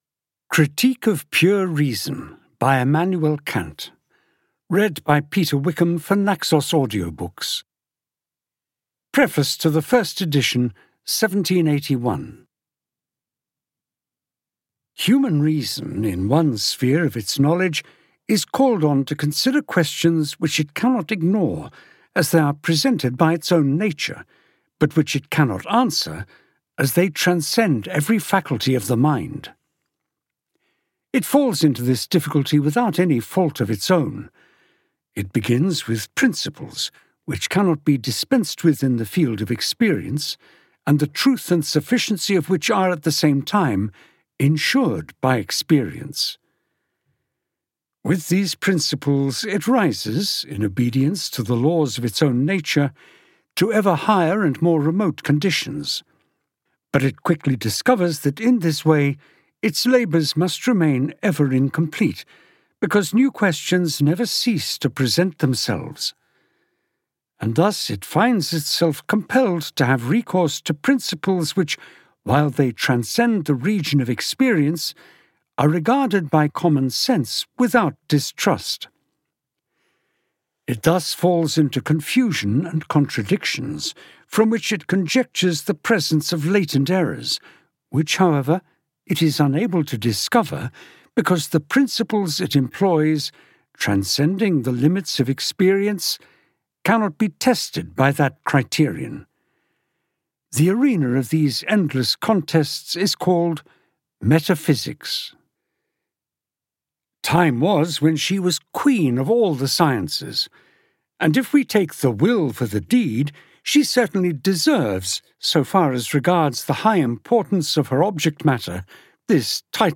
Critique of Pure Reason (EN) audiokniha